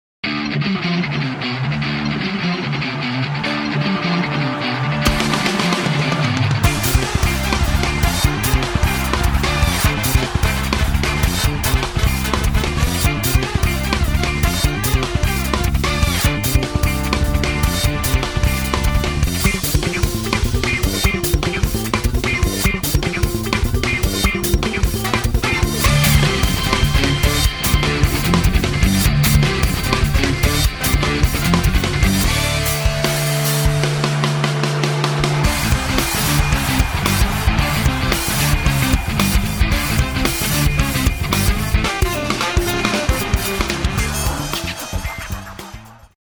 Great drum performances with all Class A mics & preamps, great kit, pro recording studio
Pop Rock Funk Blues Country